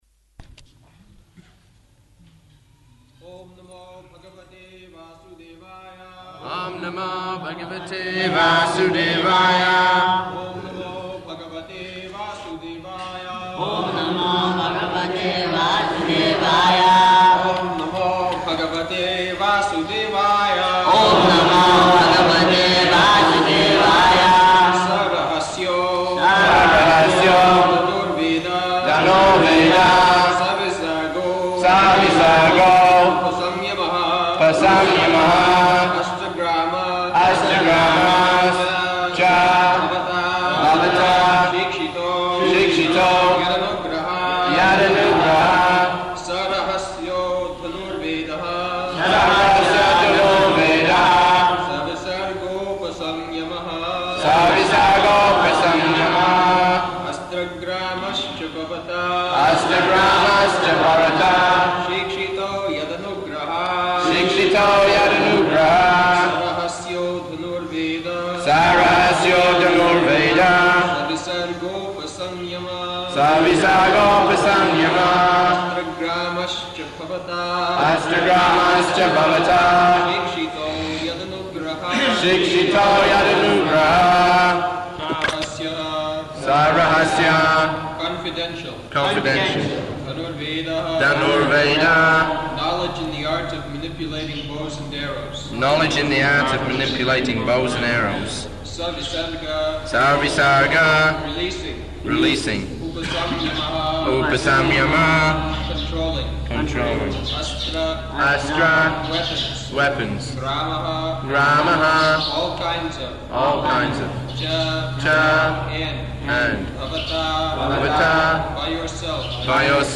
October 4th 1976 Location: Vṛndāvana Audio file